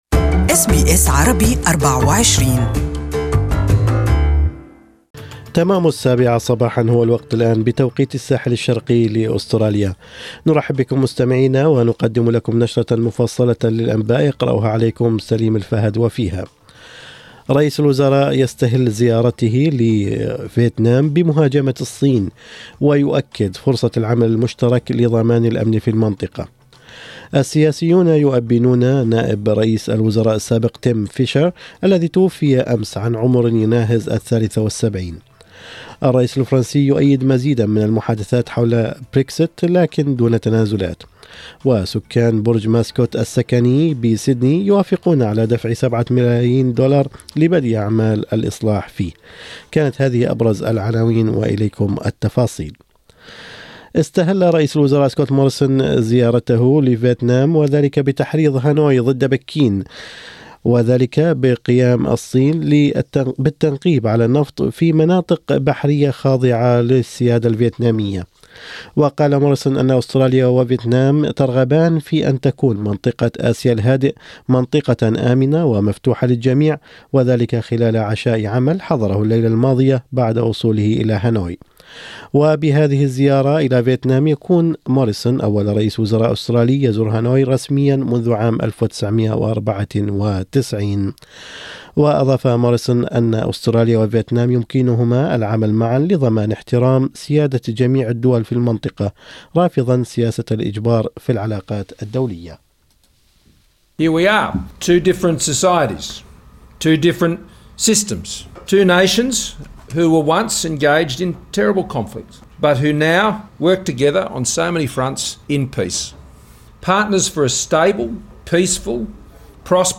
Morning News: Prime Minister Scott Morrison calls for calm in southeast Asia